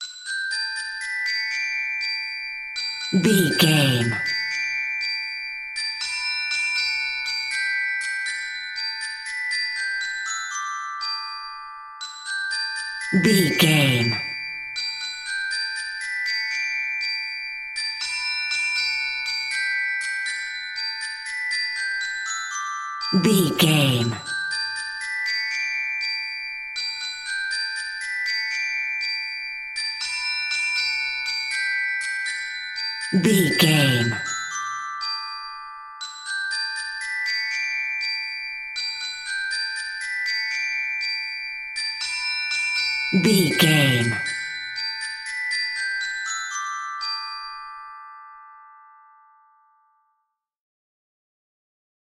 Ionian/Major
Fast
nursery rhymes
kids music